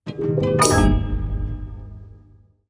UI_SFX_Pack_61_17.wav